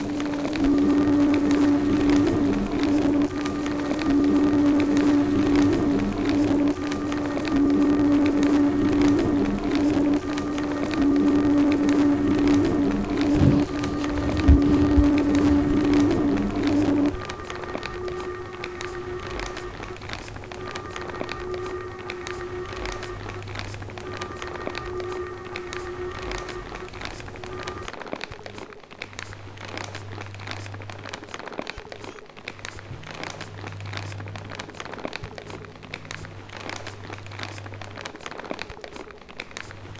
Listen to the eerie sounds of space debris soaring above your head
The Adrift project used an electromechanical instrument to transform the silent movement of 27,000 pieces of space junk into sound
The sounds engraved into the grooves were generated from recordings made by 250 individual pieces of so-called 'earthly debris'.